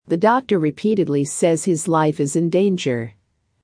このままの速度でお聞きください。
ディクテーション第1問
【ノーマル・スピード】
大きな文字:強勢のある母音
下線:音の連結　( ):子音の脱落